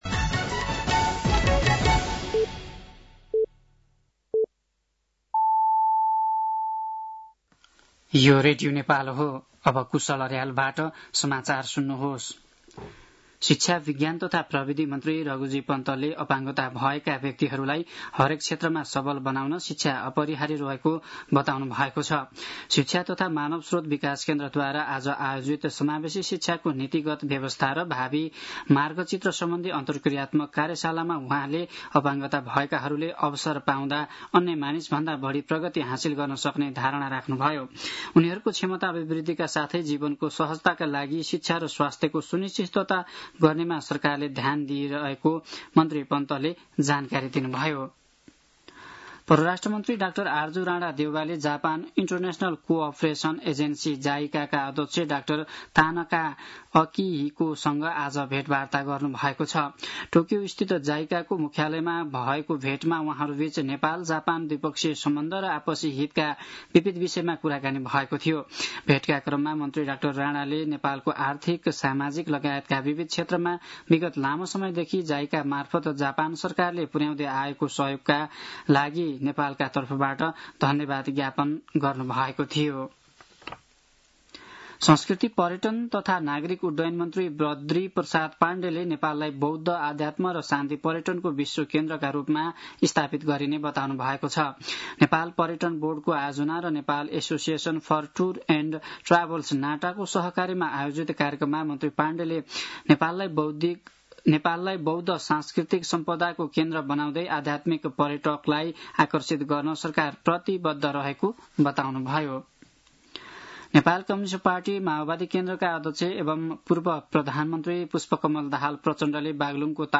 साँझ ५ बजेको नेपाली समाचार : ९ जेठ , २०८२
5-pm-nepali-news-2-09.mp3